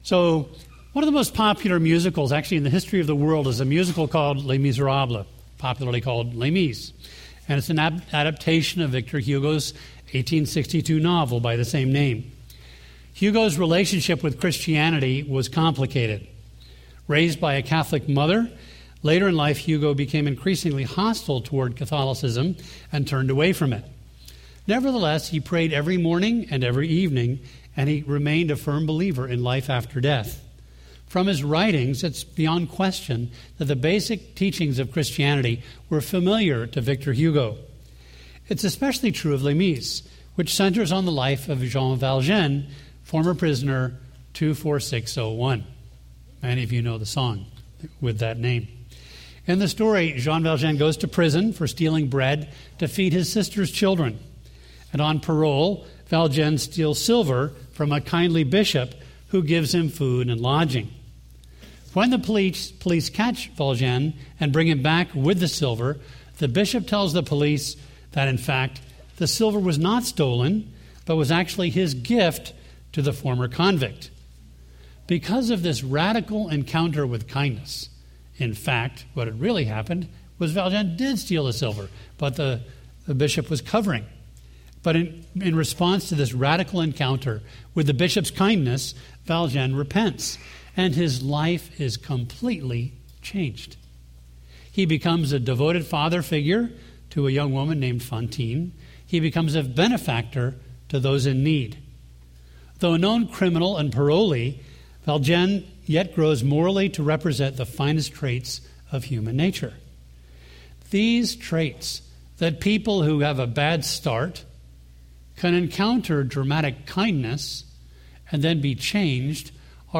A message from the series "Jonah: Faith in Action ."